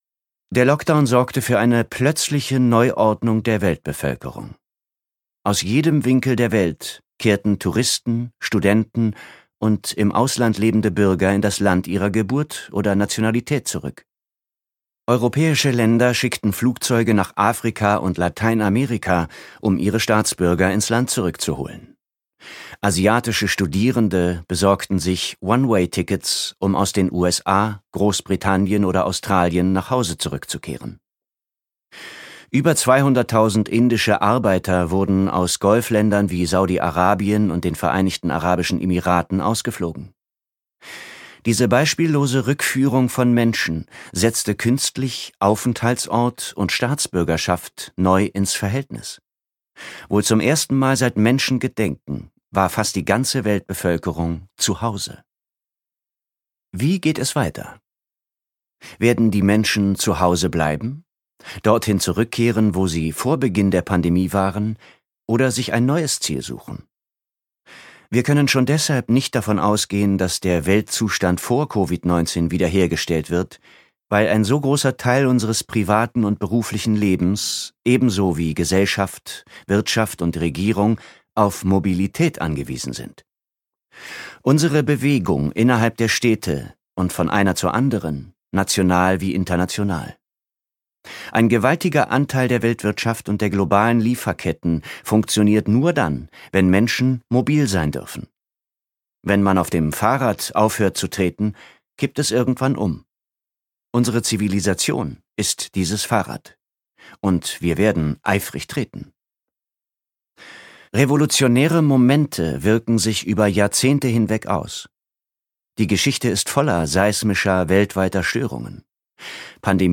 Move - Parag Khanna | argon hörbuch
Gekürzt Autorisierte, d.h. von Autor:innen und / oder Verlagen freigegebene, bearbeitete Fassung.